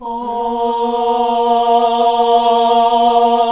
AAH A#1 -L.wav